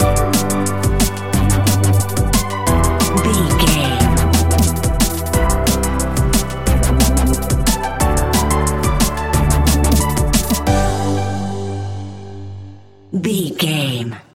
Ionian/Major
Fast
high tech
uplifting
lively
futuristic
hypnotic
industrial
frantic
drum machine
synthesiser
electronic
sub bass
instrumentals
synth leads
synth bass